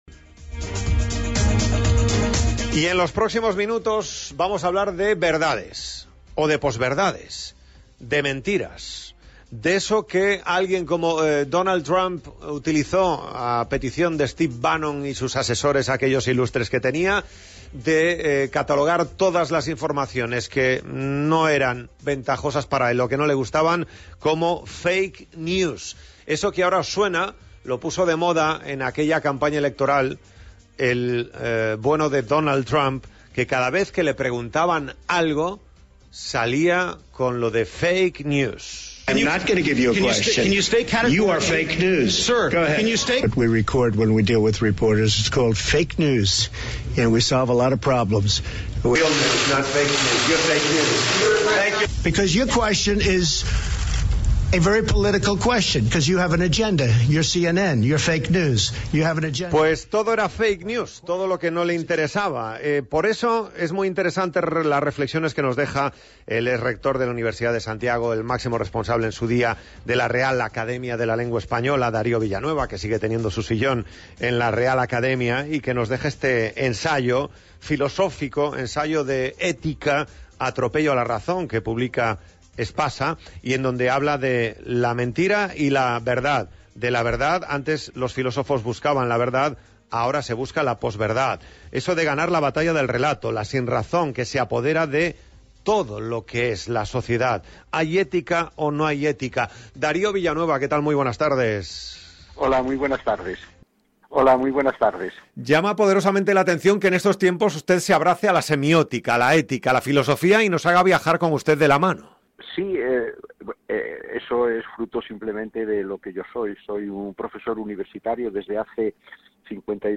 Entrevista a Darío Villanueva por su nuevo libro "El atropello a la razón"